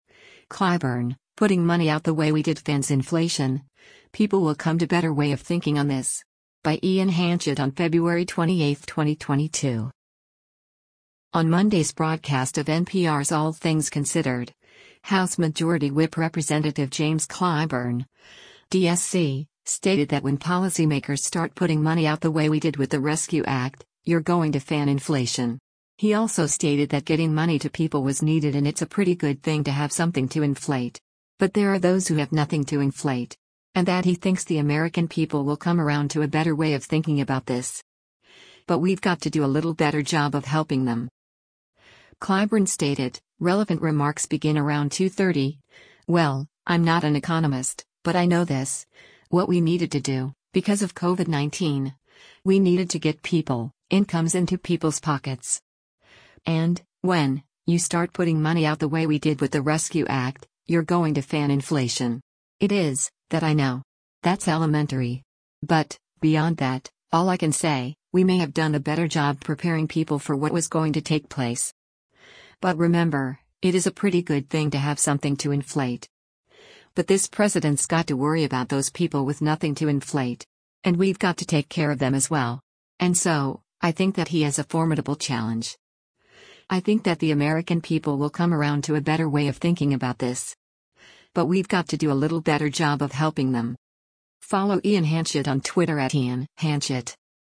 On Monday’s broadcast of NPR’s “All Things Considered,” House Majority Whip Rep. James Clyburn (D-SC) stated that when policymakers “start putting money out the way we did with the rescue act, you’re going to fan inflation.” He also stated that getting money to people was needed and it’s a “pretty good thing to have something to inflate.”